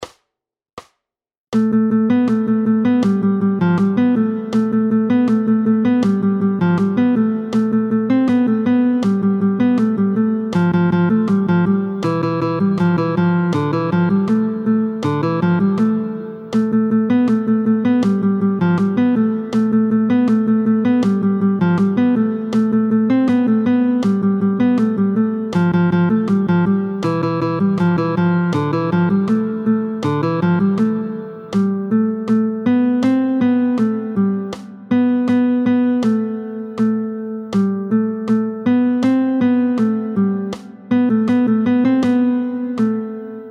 √ برای ساز گیتار | سطح متوسط